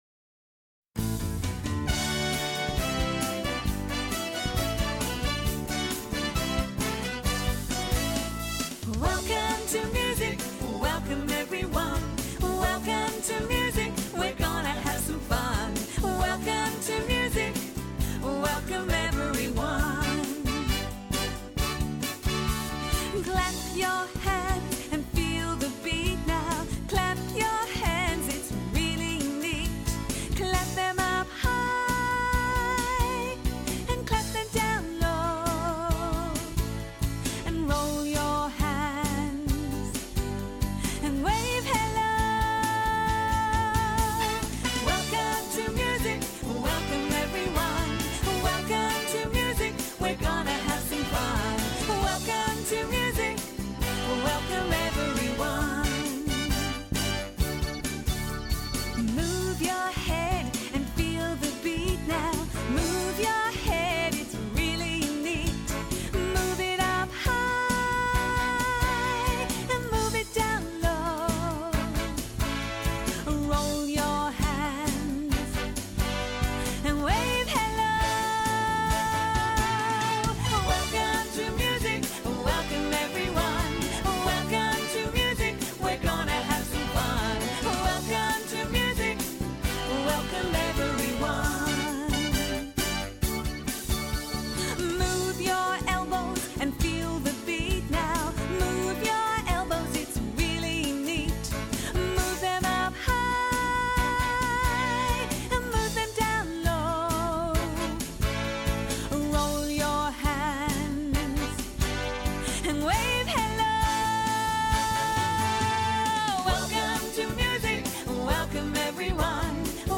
Voicing: Unison